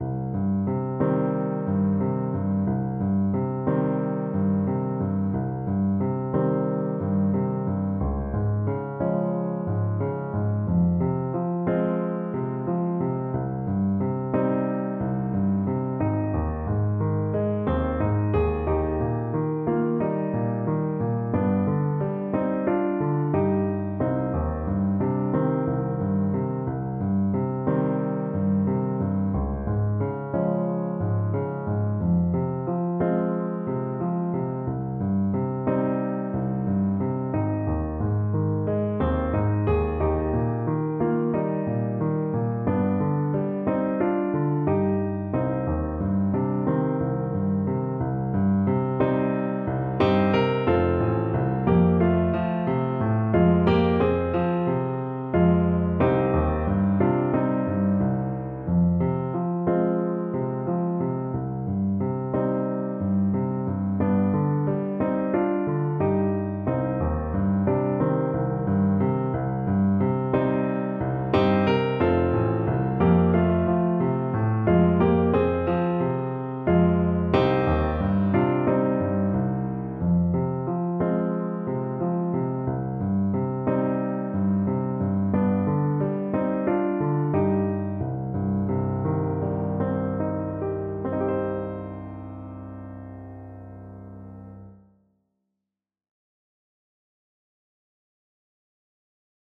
4/4 (View more 4/4 Music)
Gently Flowing =c.90
Classical (View more Classical Trombone Music)